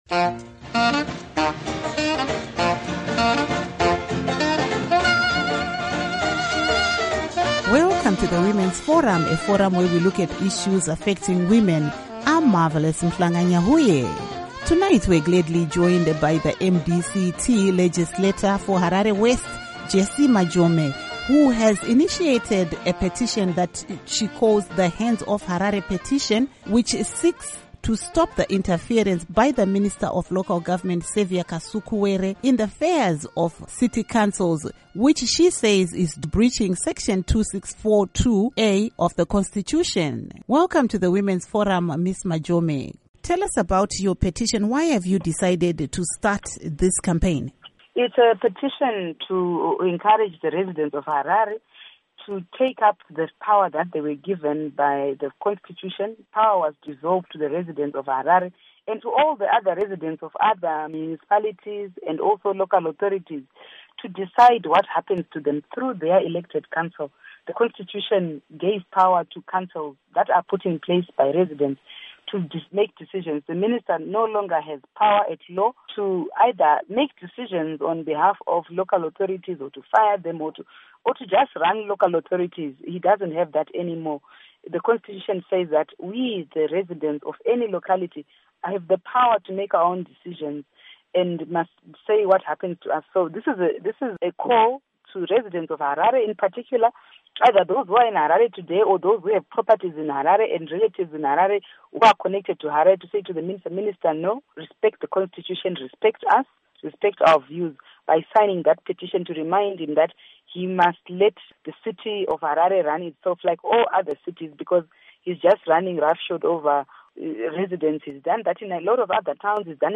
Interviews MDC-T MP Jesse Majome